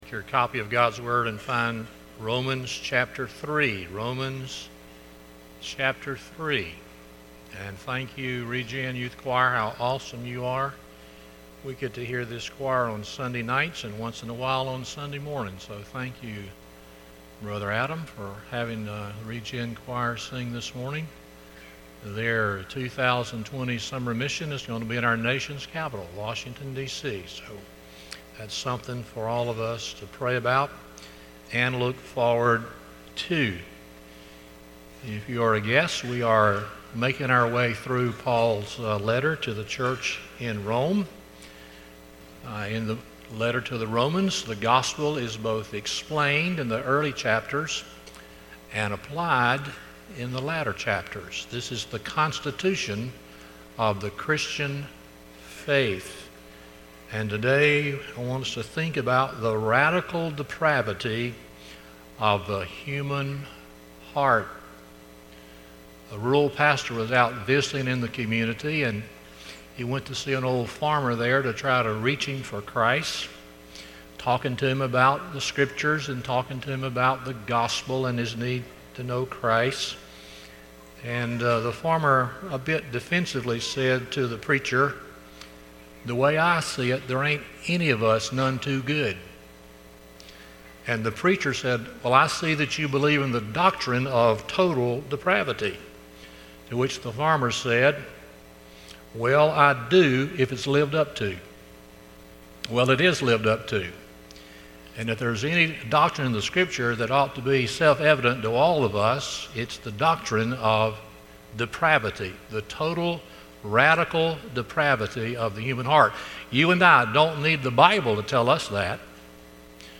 Romans 3:1-20 Service Type: Sunday Morning 1.